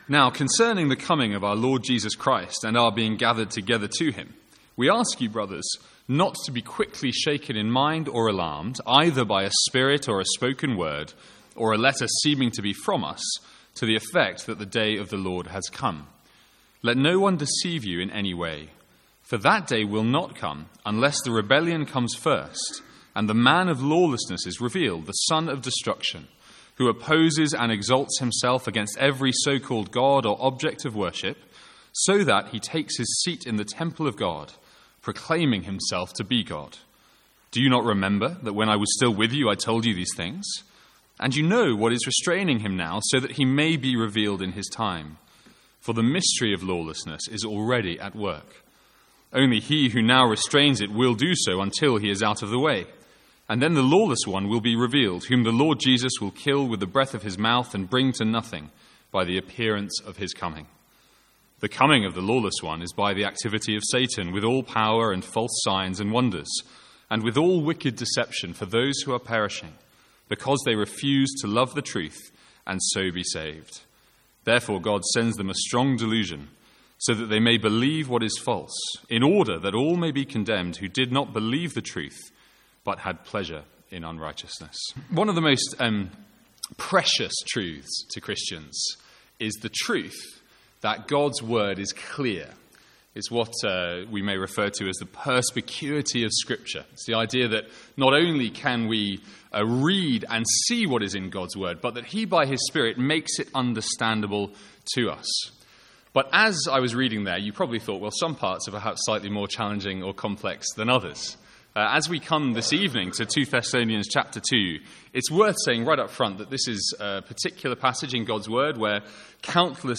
Sermons | St Andrews Free Church
From the Sunday evening series in 2 Thessalonians.